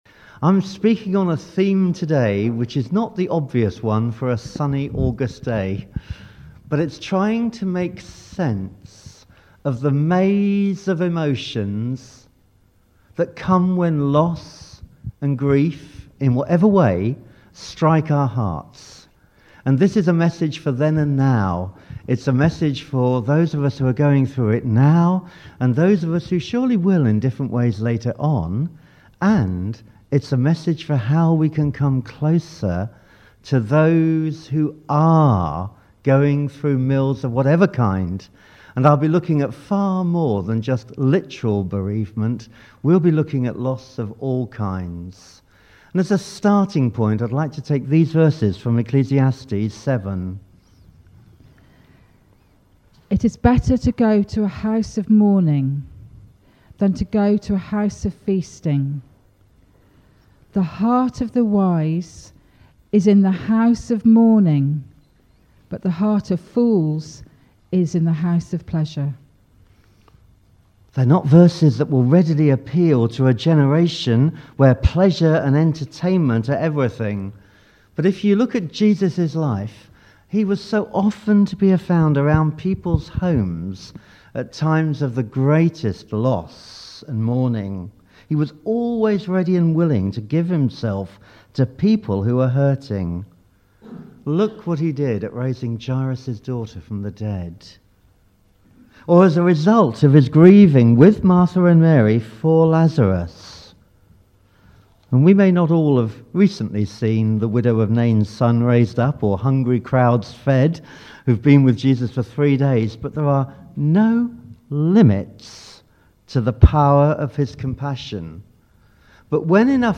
Processing Grief and Handling Loss I gave a talk in church recently on processing grief and handling loss. This has been divided into five sections set to beautifully sensitive music.